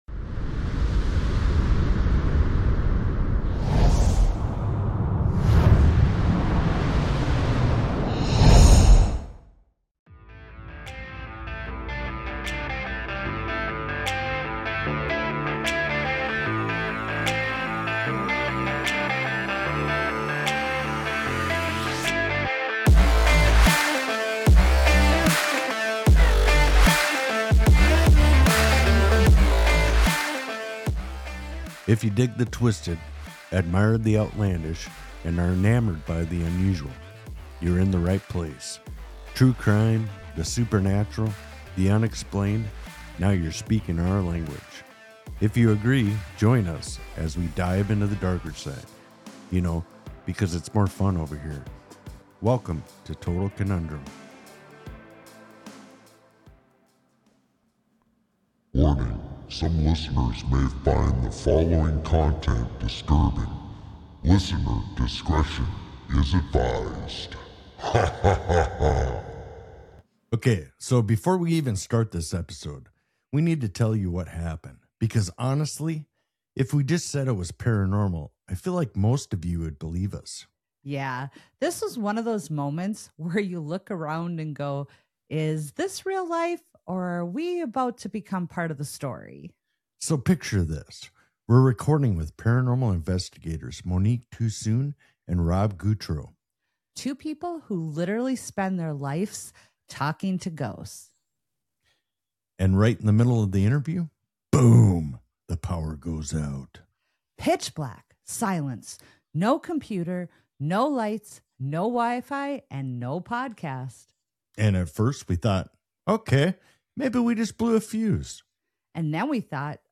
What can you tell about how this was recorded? Yes — a total blackout hits during the recording, adding a whole new layer of atmosphere (